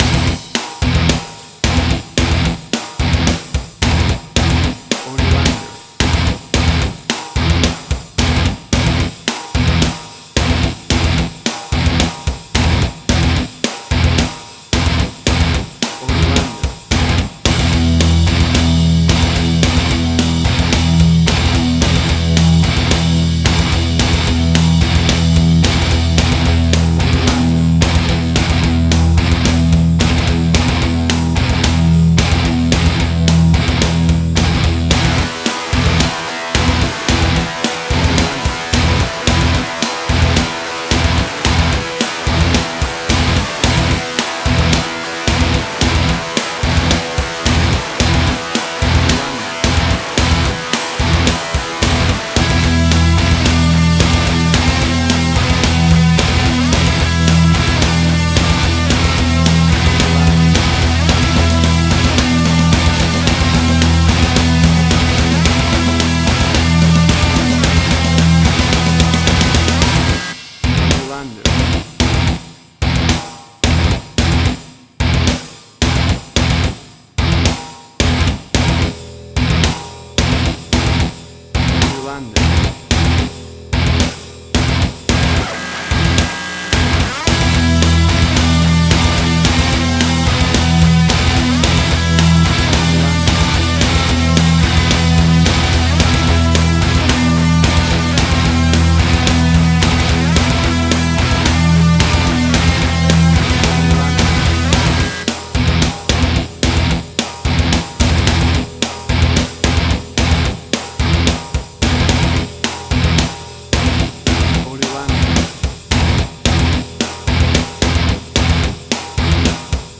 Hard Rock, Similar Black Sabbath, AC-DC, Heavy Metal.
Tempo (BPM): 110